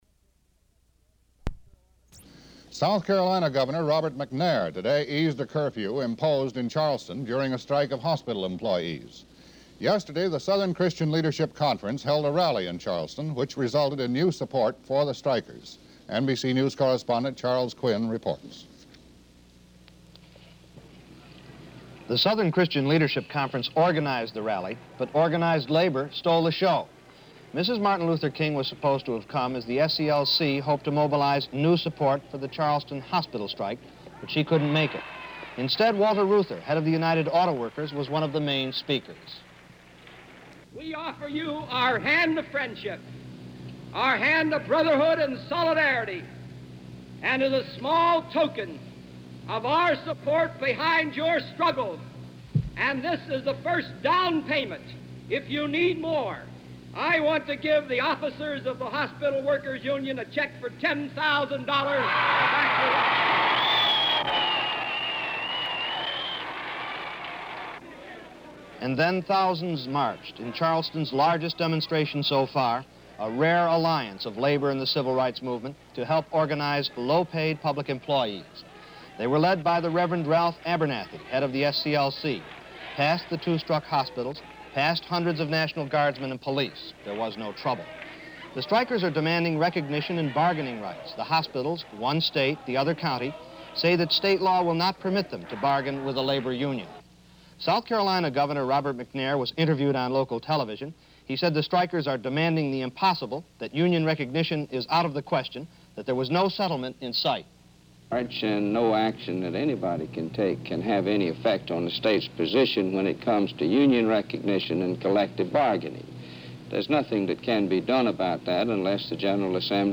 President Walter P. Reuther - Hospital Workers Rally, Charleston, South Carolina